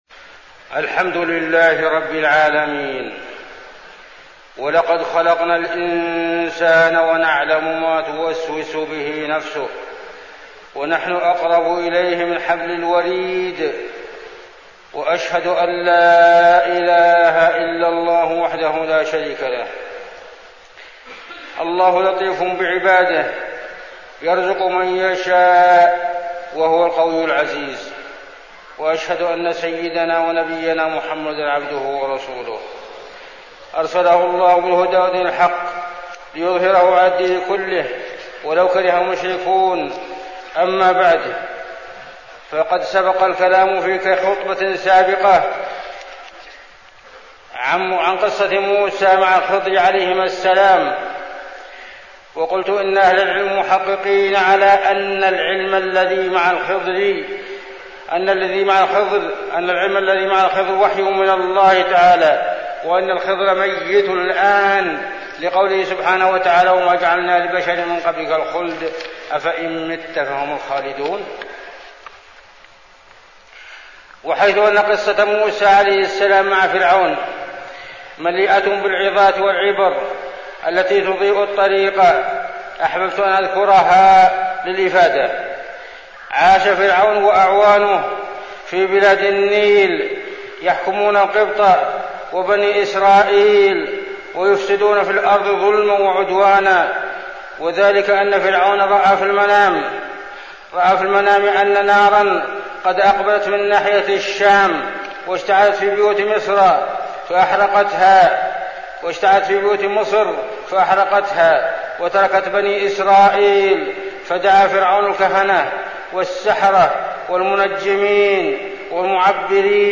تاريخ النشر ٢٢ صفر ١٤١٨ هـ المكان: المسجد النبوي الشيخ: عبدالله بن محمد الزاحم عبدالله بن محمد الزاحم موسى وفرعون